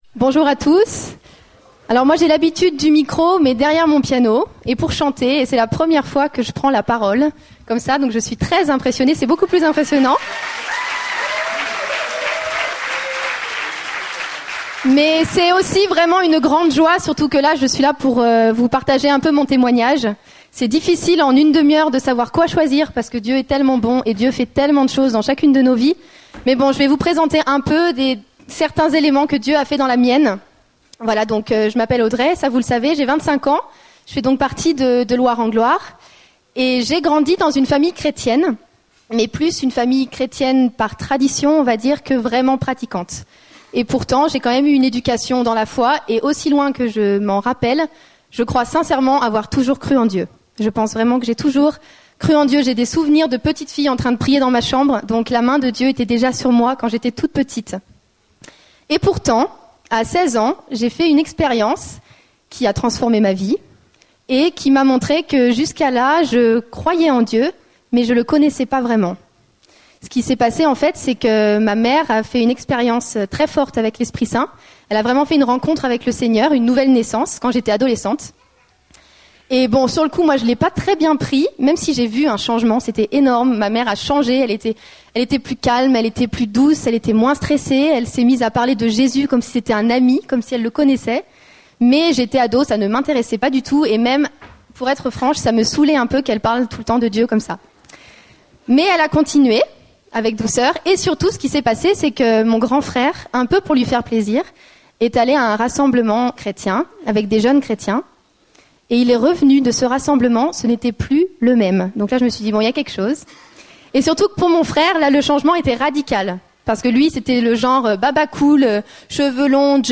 Format : MP3 64Kbps Mono